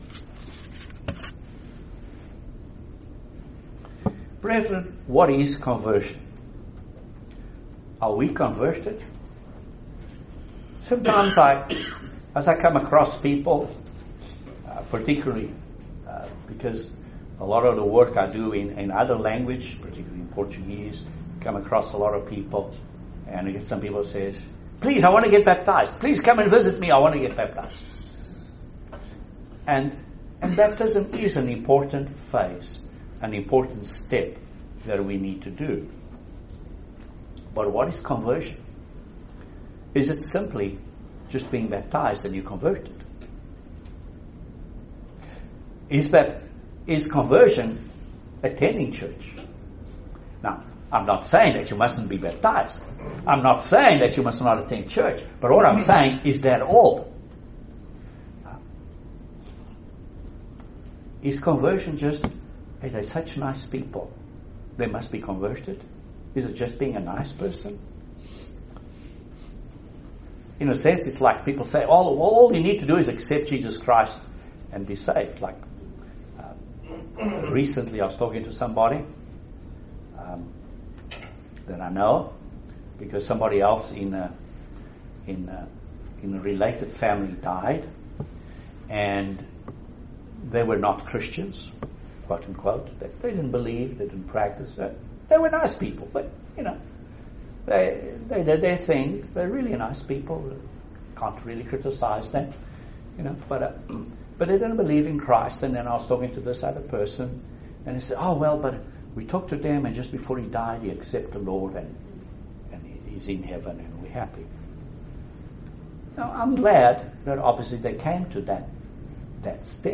Excellent sermon on the subject of true conversion.